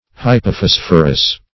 Hypophosphorous \Hy`po*phos"phor*ous\, a. [Pref. hypo- +
hypophosphorous.mp3